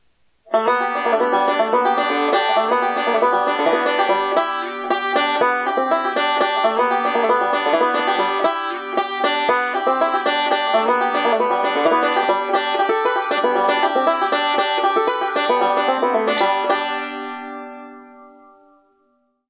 Danville Banjo - $325 + $75 S/H (US only)
This is a great sounding banjo for the money!